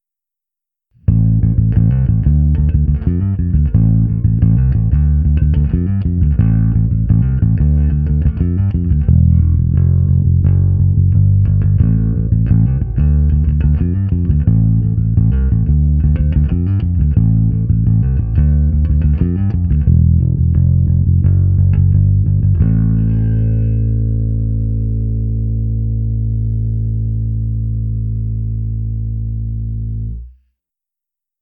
Je hutný, vrčivý, zvonivý, s bohatými středy, s příjemnými výškami a masívními basy.
Tentokrát se mi nechtělo rozštelovávat si můj preamp od Darkglassu, takže jsem pro ukázku toho, jak hraje basa přes aparát, použil simulaci softwarem AmpliTube 4.
Ukázka přes AmpliTube